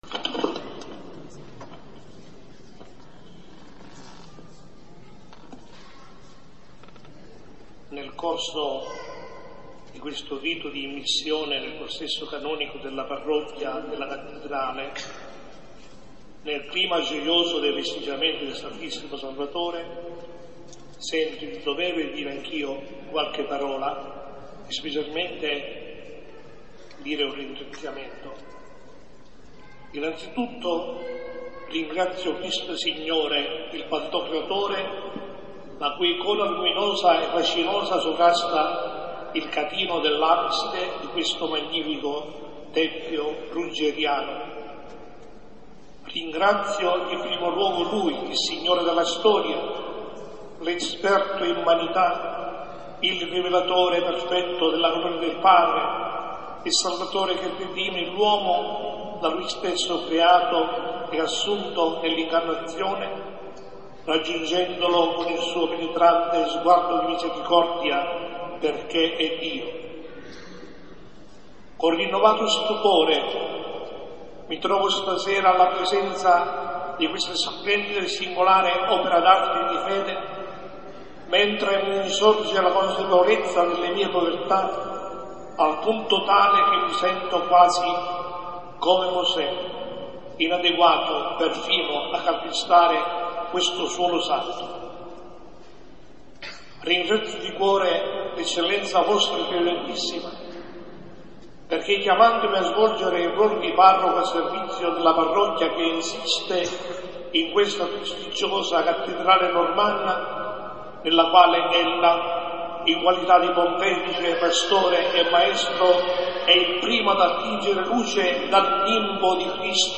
Liturgia Lucernale.
Discorso